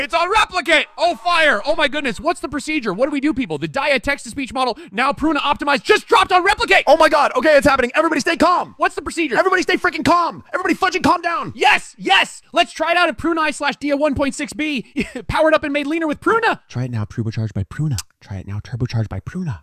Craft dynamic multi-speaker dialogues with non-verbal cues.
Text-to-SpeechDialogue GenerationAudio Synthesis
• Non-verbal cue integration (e.g., laughs, whispers)
"speed_factor": 0.94,